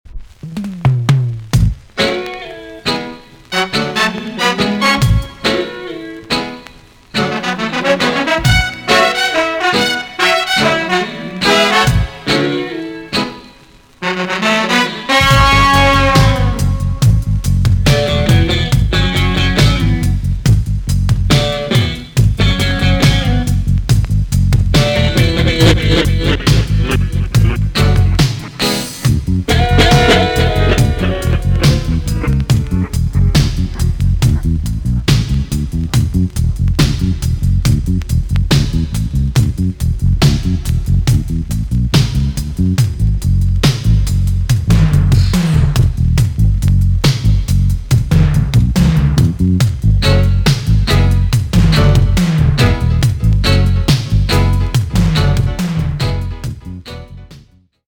B.SIDE Version
EX-~VG+ 少し軽いチリノイズが入りますが良好です。